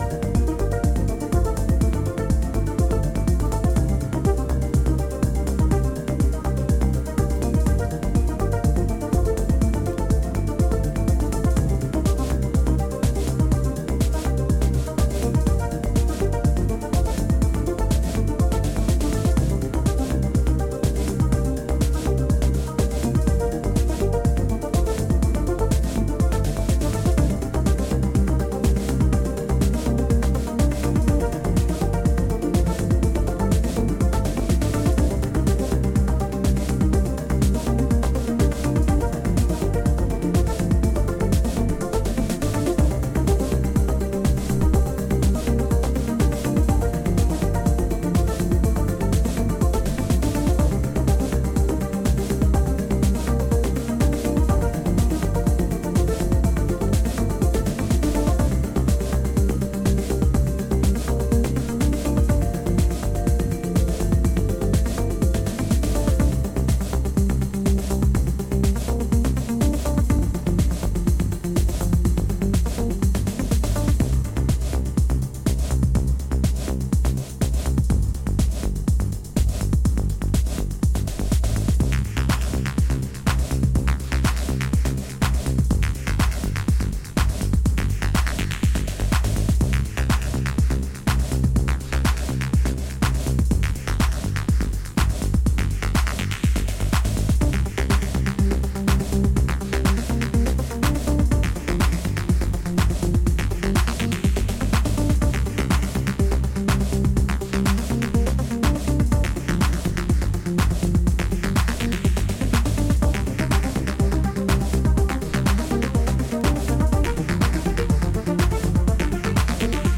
煌めくシンセアルペジオが乱反射する上品なテック・ハウス